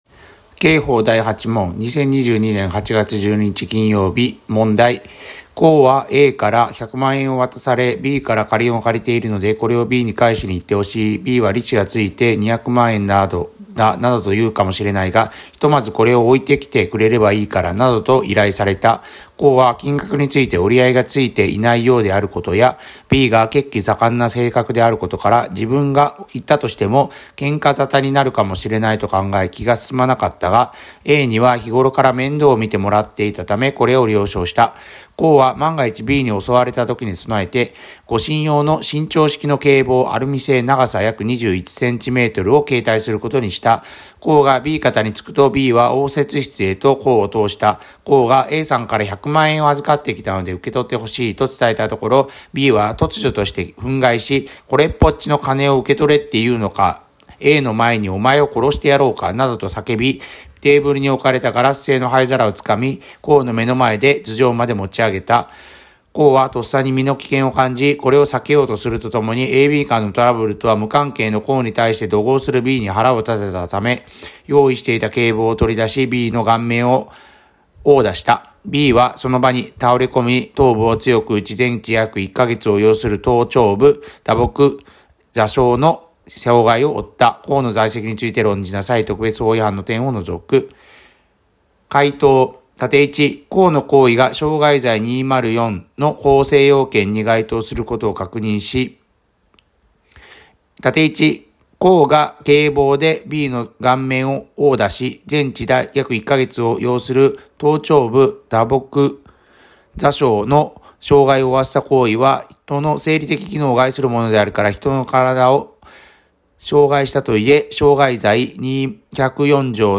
問題解答音読